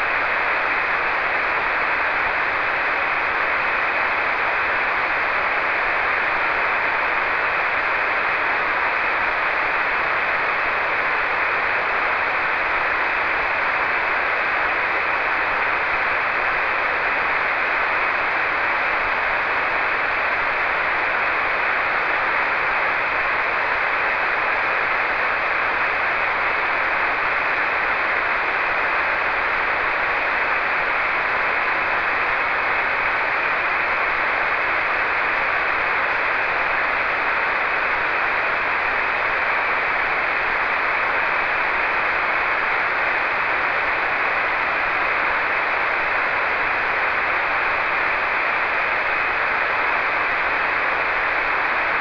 Prove di ascolto WSJT - 8,9 aprile 2011
Antenna: 16JXX, Apparato: FT897
nessun preamplificatore
N.B.: Solo nel primo file si può ascoltare un flebile "suono"... per tutti gli altri solo utilizzando WSJT si ha evidenza dei segnali ricevuti.